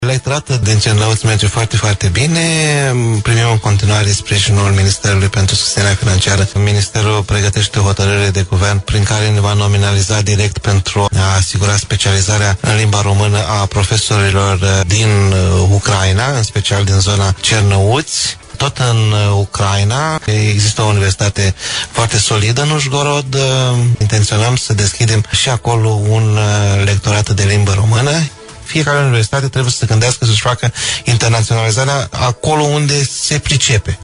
Rectorul Universității “Ștefan cel Mare” Suceava, VALENTIN POPA, a apreciat, la Radio VIVA FM, că atestatele de certificare lingvistică contribuie la întărirea relațiilor dintre cele două state.